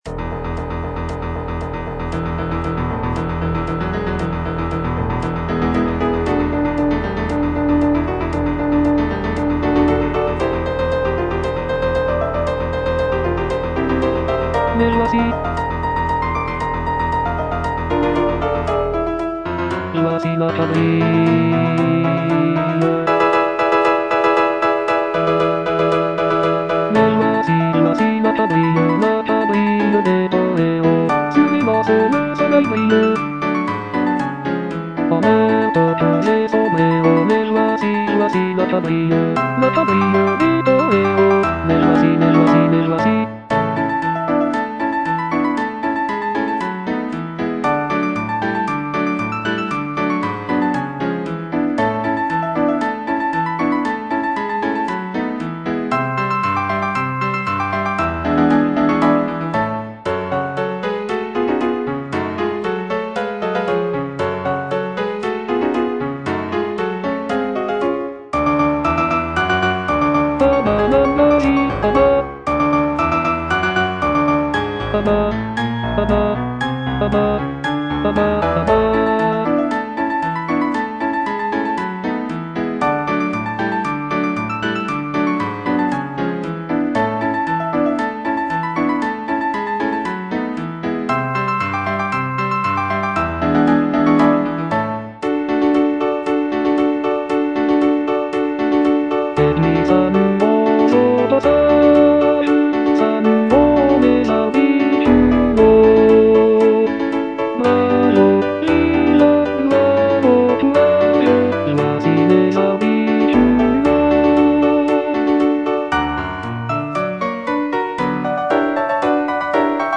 G. BIZET - CHOIRS FROM "CARMEN" Les voici - Bass (Voice with metronome) Ads stop: auto-stop